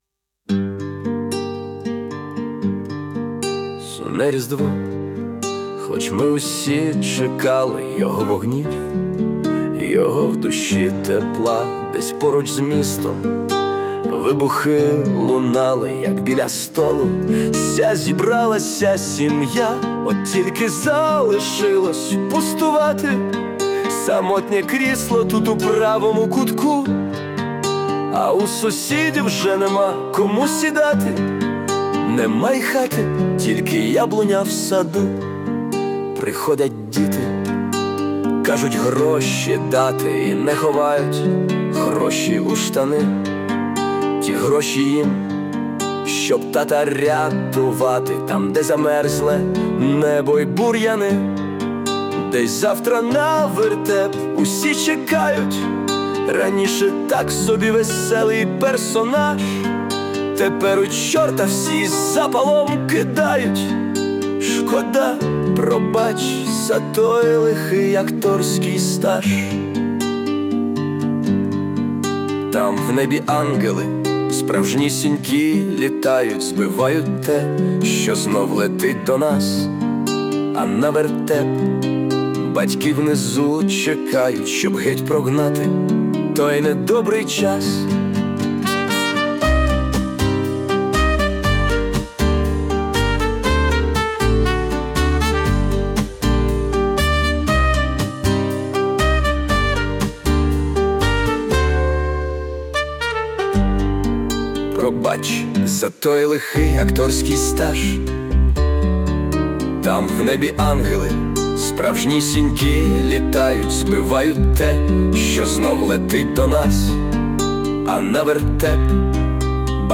Музична композиція із залученням ШІ
Поєднання веселого з сумним є свідомим